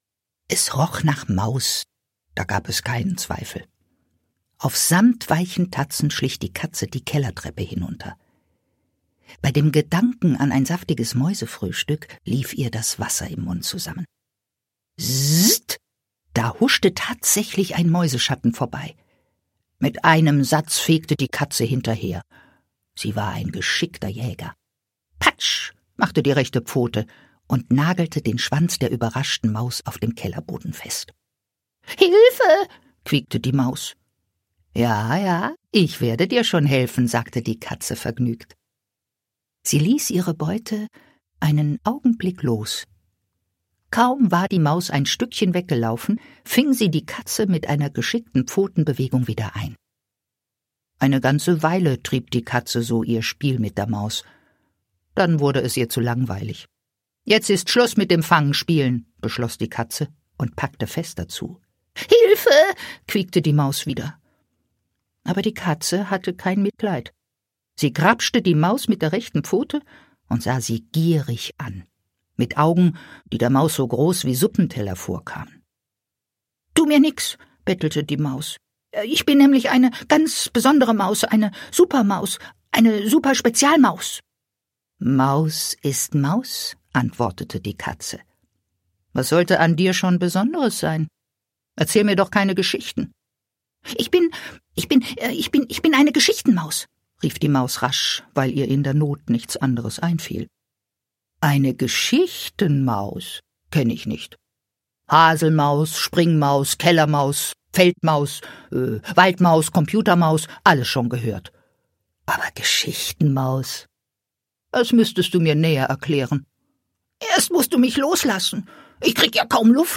Schlagworte Hörbuch; Lesung für Kinder/Jugendliche • Katze • Katzen • Kinder • Maus • Mäuse • Maus / Mäuse • Maus / Mäuse (Tier) • Maus (Tier)